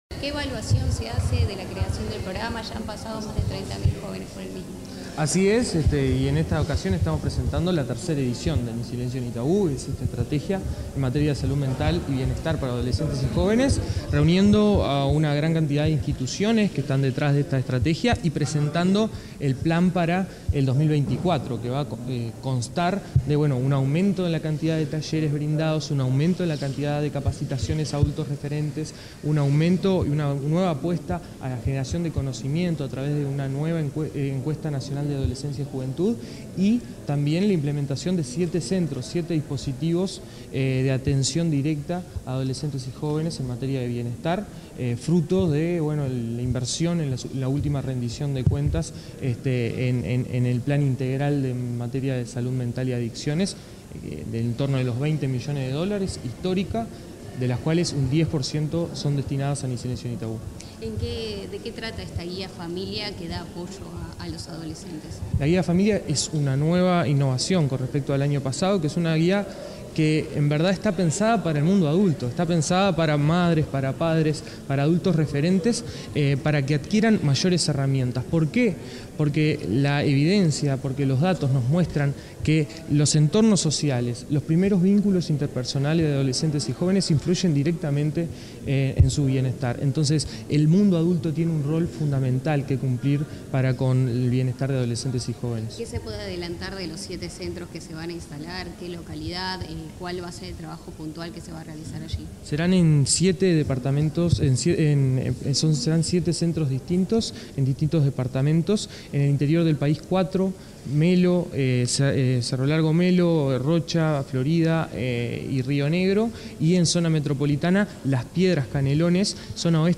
Declaraciones del director del INJU, Aparicio Saravia
Declaraciones del director del INJU, Aparicio Saravia 29/05/2024 Compartir Facebook X Copiar enlace WhatsApp LinkedIn Tras participar en el lanzamiento de una nueva edición del programa Ni Silencio Ni Tabú, este 29 de mayo, el director del Instituto Nacional de la Juventud (INJU), Aparicio Saravia, realizó declaraciones a la prensa.